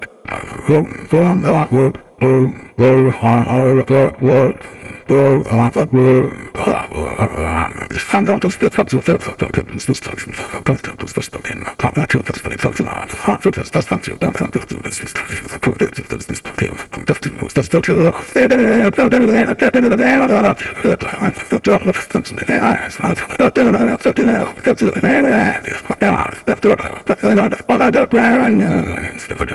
grandpa-tryina-do-the-fnafbeat-made-with-Voicemod-technology.mp3